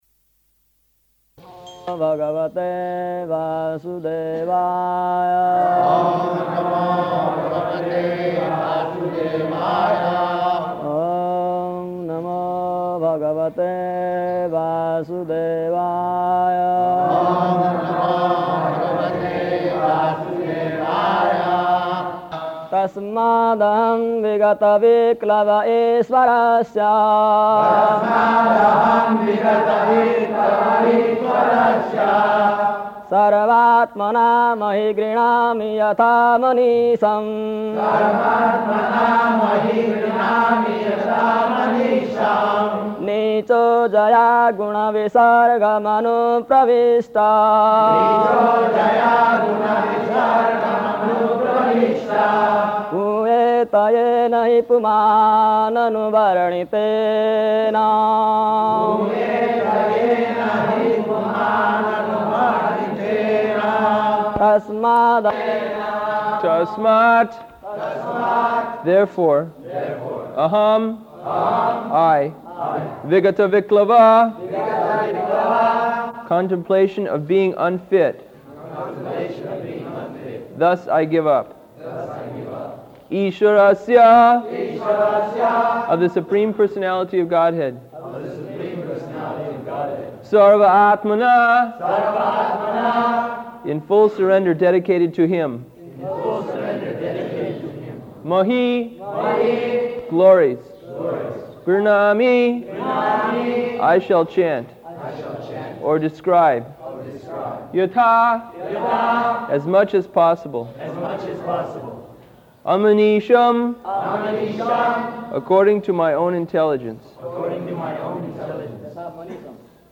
February 19th 1976 Location: Māyāpur Audio file